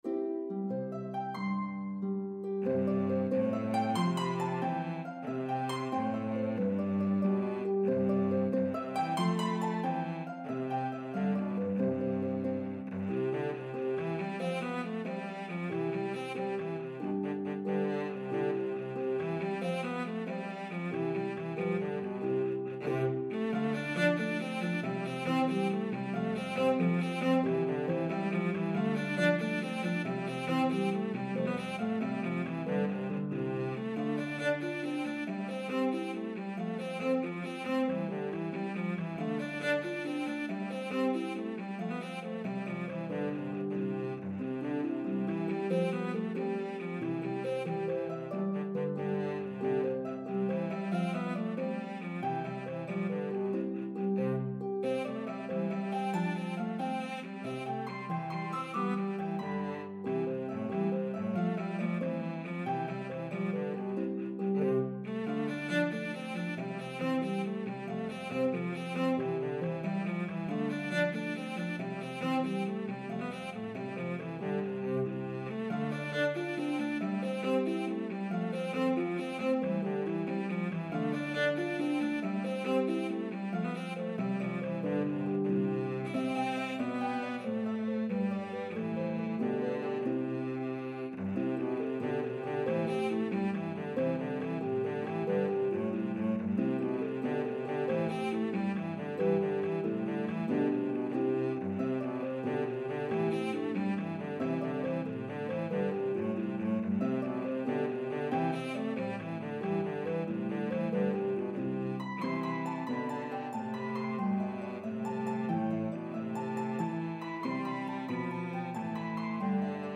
a medley of two joyful, upbeat Irish Jigs